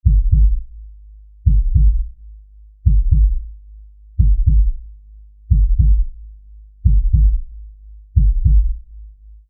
دانلود آهنگ تپش قلب 1 از افکت صوتی انسان و موجودات زنده
دانلود صدای تپش قلب 1 از ساعد نیوز با لینک مستقیم و کیفیت بالا
جلوه های صوتی